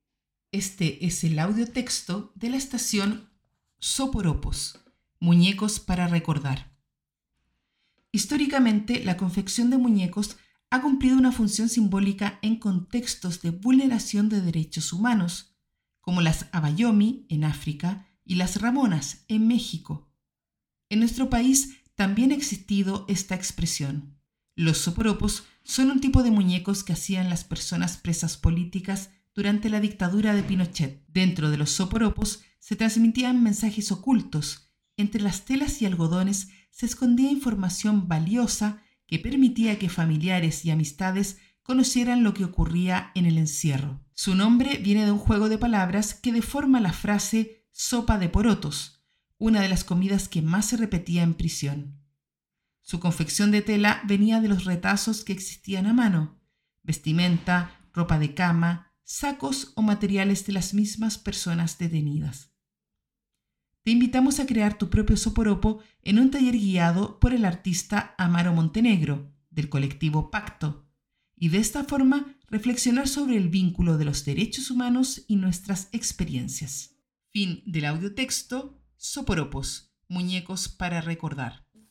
Audiotexto